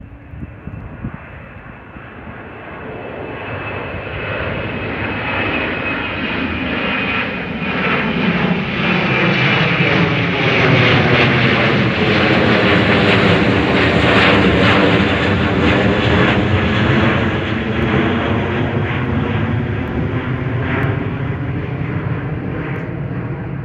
Jet Aircraft Takeoff Acoustics from Ground Measurement
I made a video using my Android Smartphone of this aircraft taking off from SeaTac airport, headed northward.
Most of the takeoff noise is due to the Boeing 737-800’s two CFM56-7B turbofan engines. Turbulent airflow over the airframe is another source.
Furthermore, the narrowbands are sweeping upward in frequency as the aircraft flew away from me past the 15 sec mark, contrary to the Doppler principle.
The noise output is actually broadband.